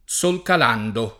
sole [S1le] s. m. — come n. pr. dell’astro, meglio con S- maiusc.; ma sempre con s- minusc. per indicare la sua apparenza (il levar del s., il s. al tramonto) o la sua luce (colpo di s., occhiali da s.) e in altre espressioni generiche o figurate (è più chiaro del s., è bella come il s.; aver qualcosa al s., volere un posto al s.; nulla di nuovo sotto il s., ecc.)